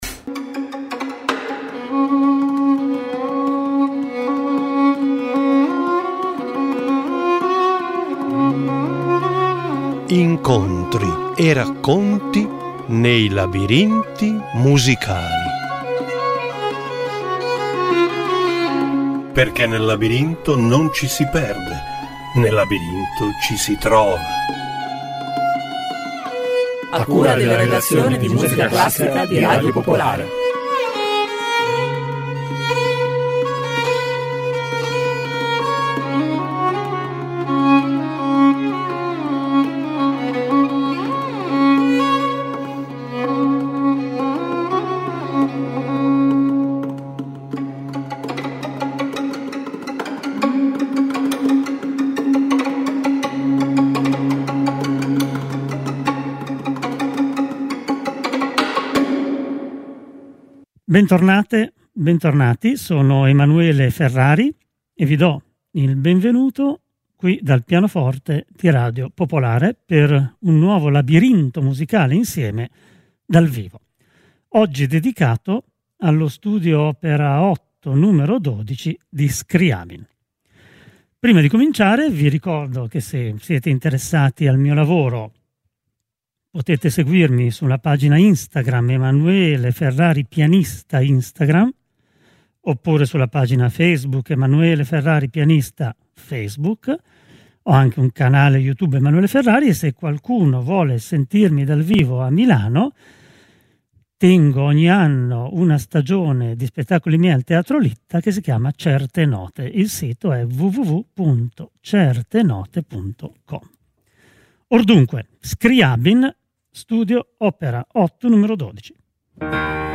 "Labirinti Musicali" ideato dalla redazione musicale classica di Radio Popolare, in ogni episodio esplora storie, aneddoti e curiosità legate alla musica attraverso racconti che intrecciano parole e ascolti.